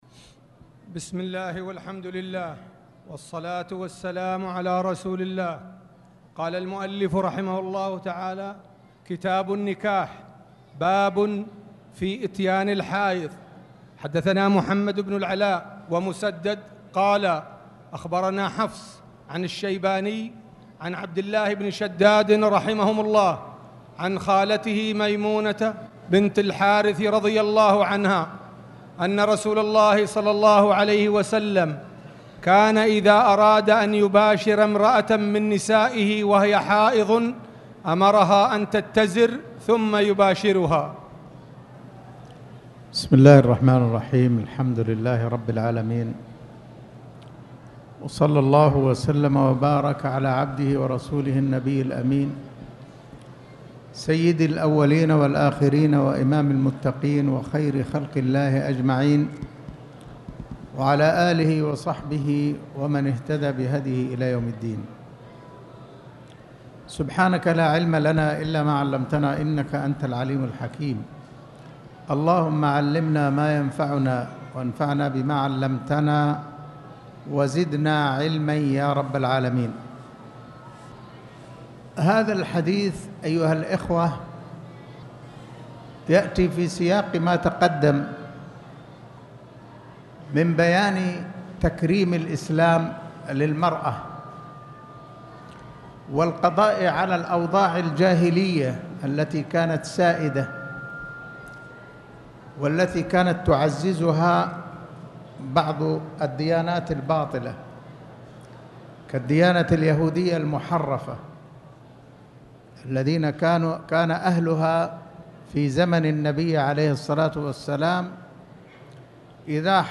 تاريخ النشر ١٥ ربيع الأول ١٤٣٨ هـ المكان: المسجد الحرام الشيخ